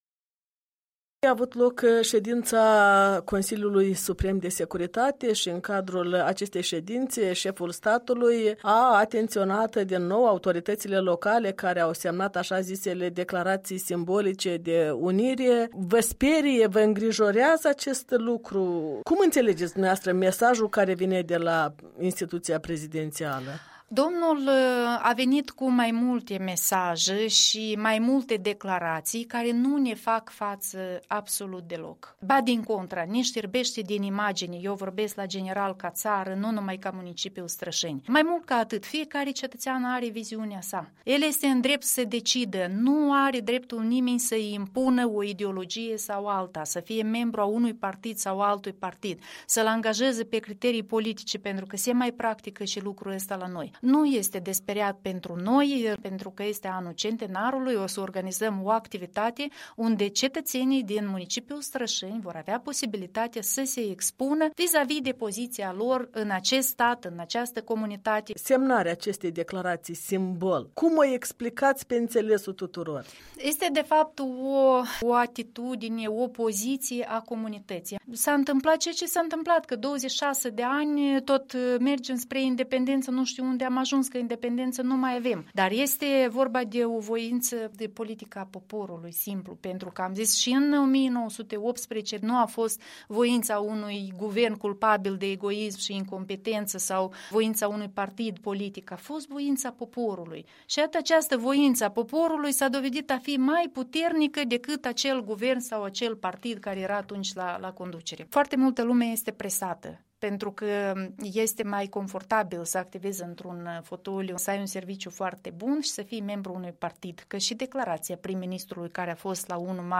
Un interviu cu primarul municipiului Strășeni.